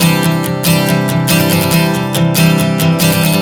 Strum 140 C 05.wav